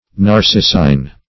Narcissine \Nar*cis"sine\, a. Of or pertaining to Narcissus.